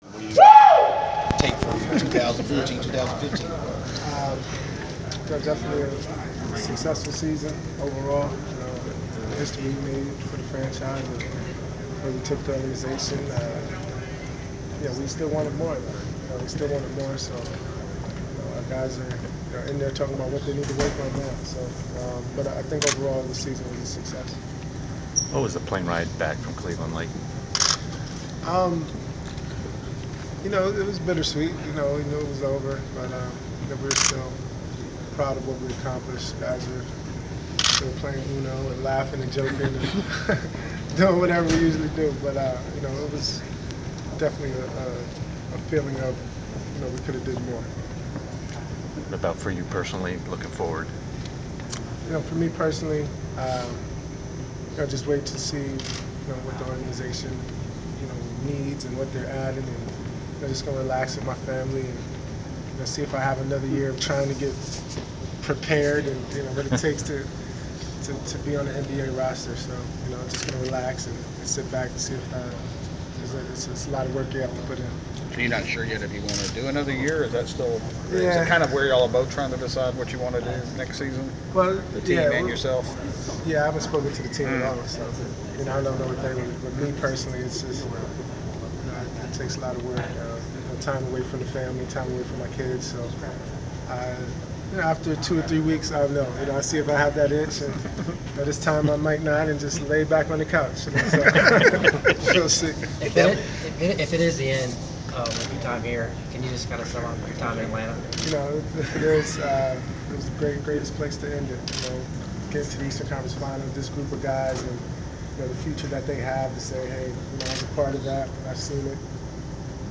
Inside the Inquirer: Postseason presser with Atlanta Hawks Elton Brand
The Sports Inquirer attended the media presser of Atlanta Hawks forward Elton Brand following the conclusion of his team’s season. Topics included the unrestricted free agent reflecting on his time in Atlanta, contemplating retirement and the Hawks’ season overall.